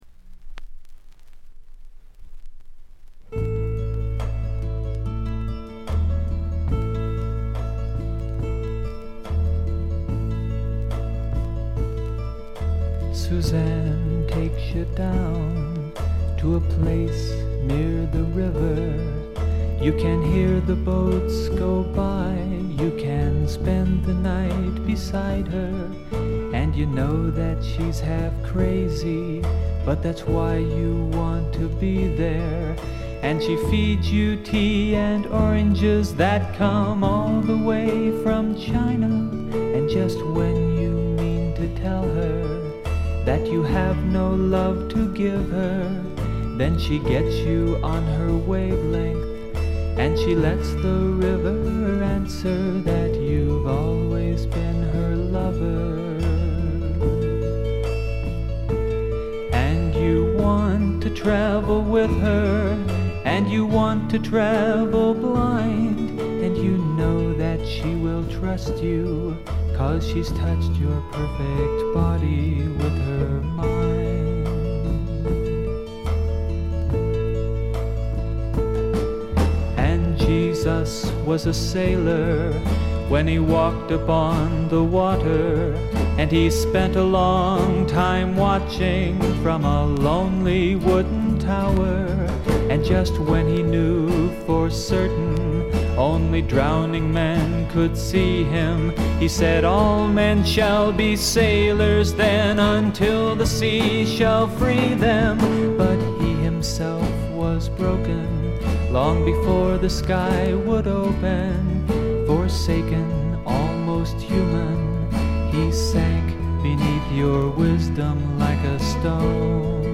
ごくわずかなノイズ感のみ。
マイナーなフォーキー・シンガーソングライター
試聴曲は現品からの取り込み音源です。
Guitar, Vocals Liner Notes